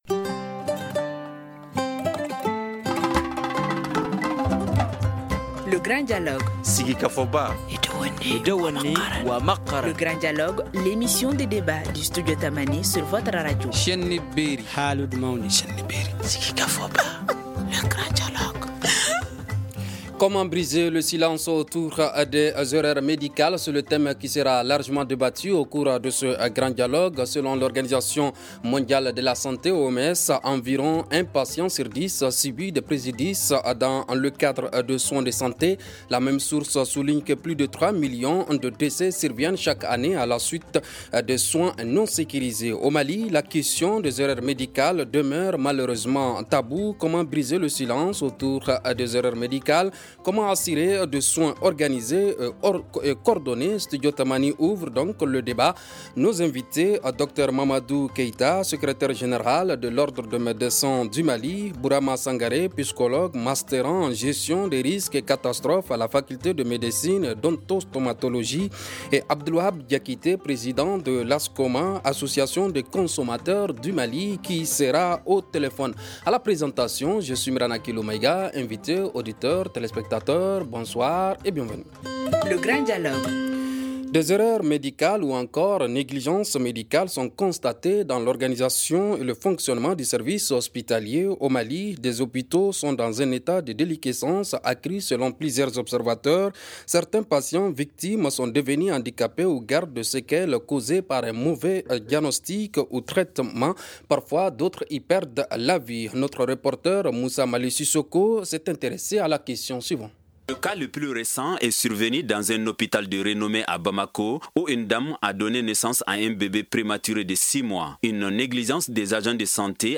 Studio Tamani ouvre le débat :